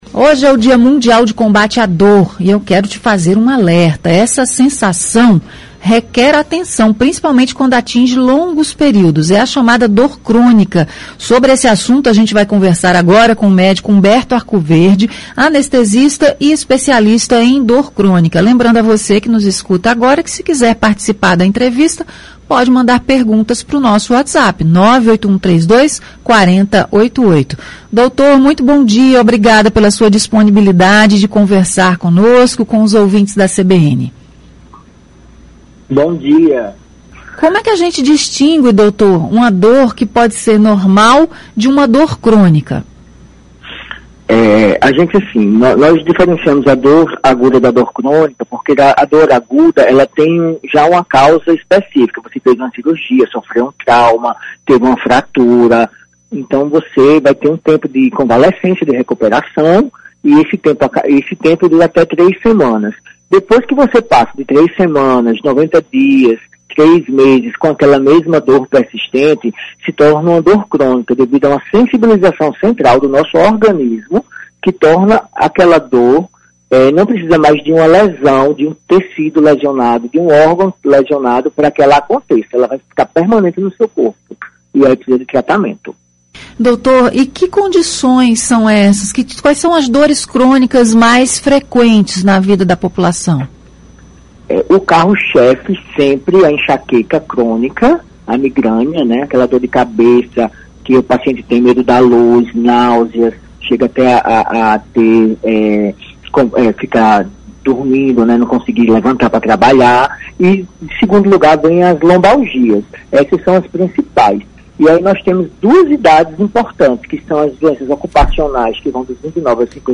Entrevista: médico alerta para dor crônica